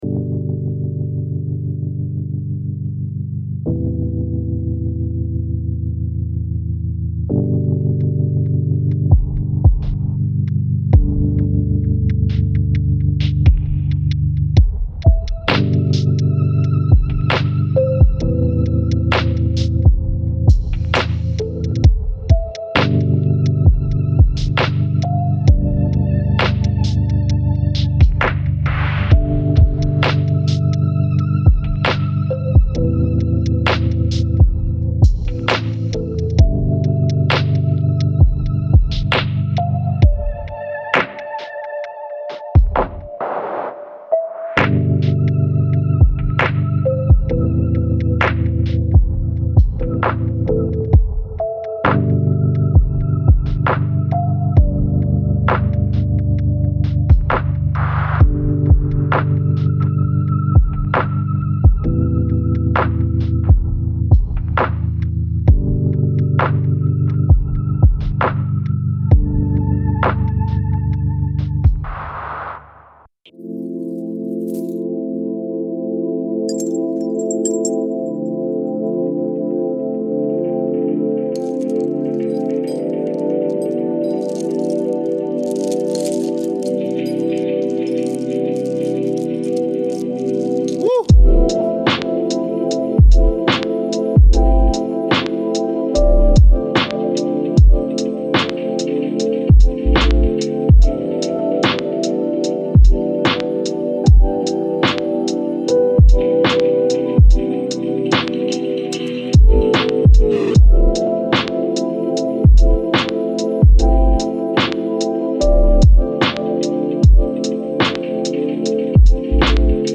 Sommeil 432 Hz : Repos Immédiat